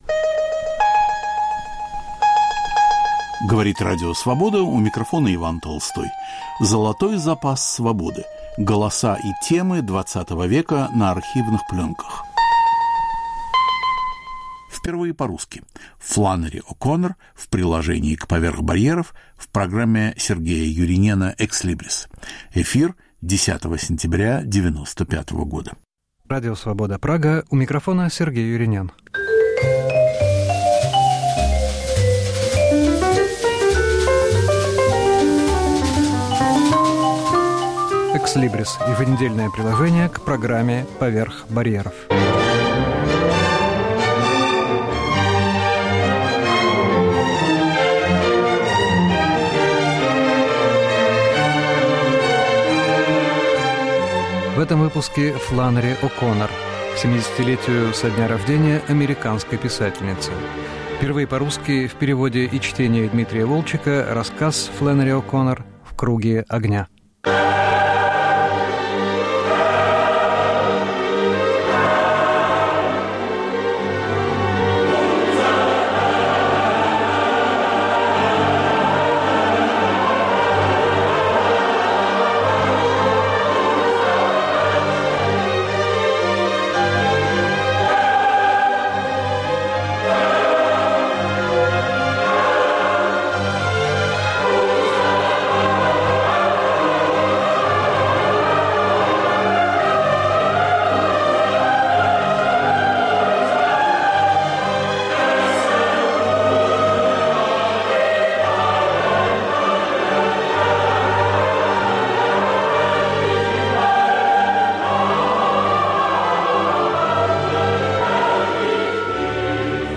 перевел и читает